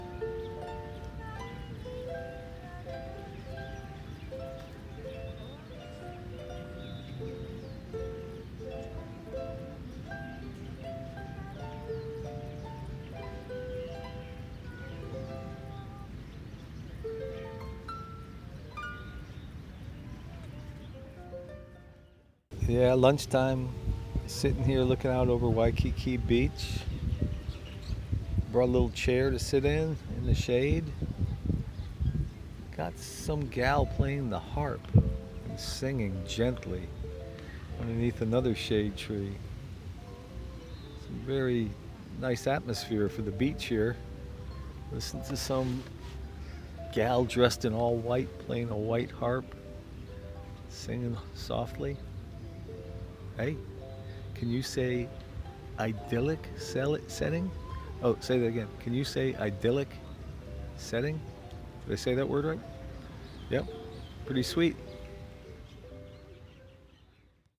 Seaside lunching
As I sat and started eating my lunch, I heard the tranquil play of a harp, and some gentle singing behind me.
The lady in white providing soothing sounds